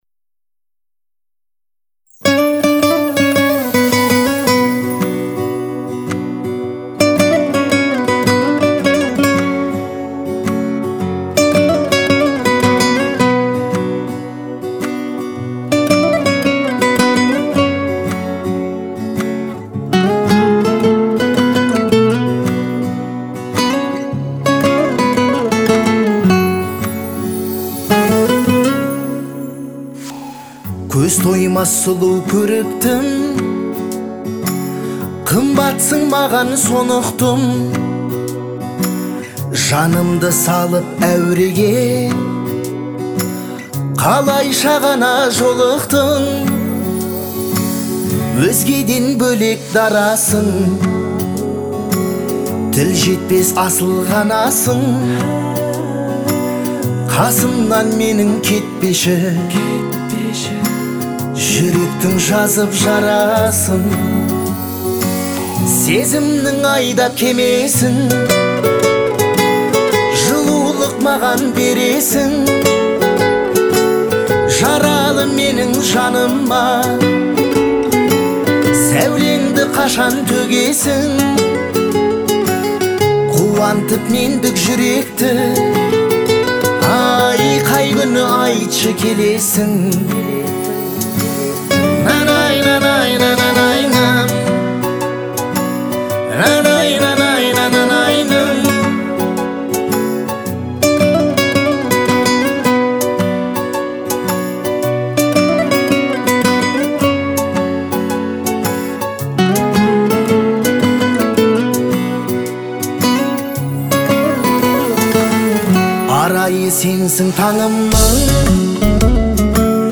это чувственная и мелодичная песня